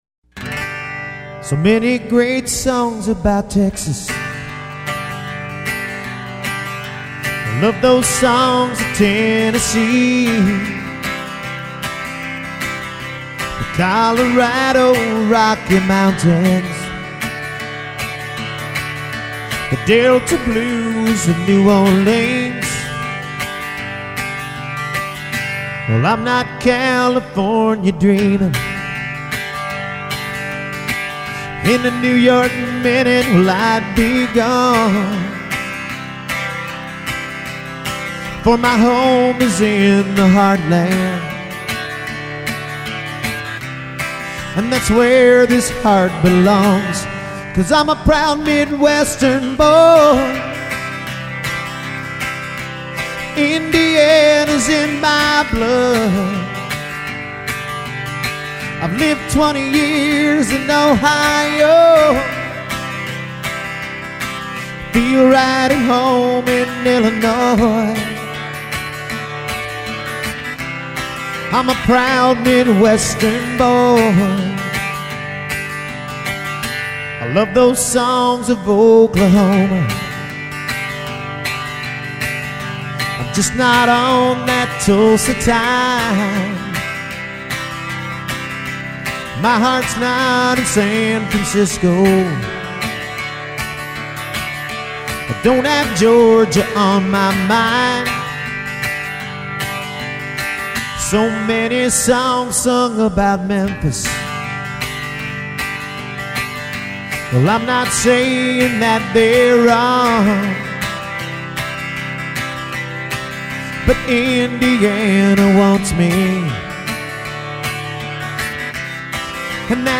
Lead and Background Vocals, Acoustic Guitar
Keyboards, Synth Bass, Drum Programming